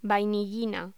Locución: Vainillina
voz